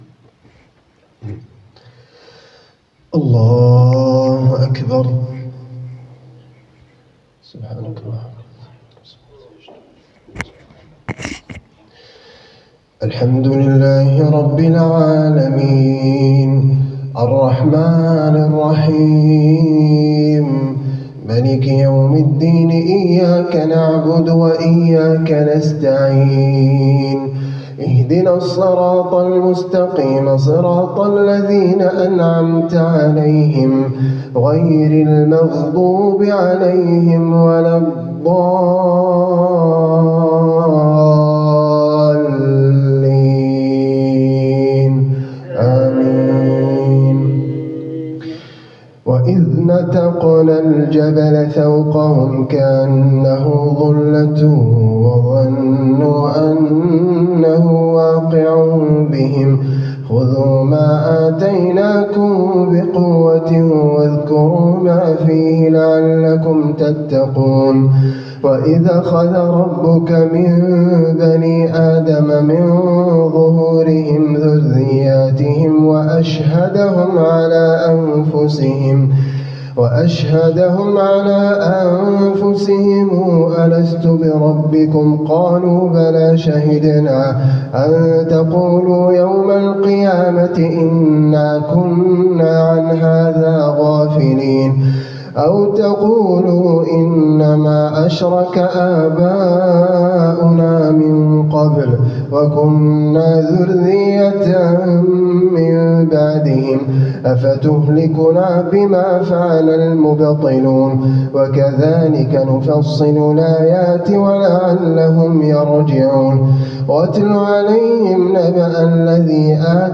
جديد - من سورة الأعراف.تراويح الليلة 9 من رمضان 1447ه‍.نسأل الله القبول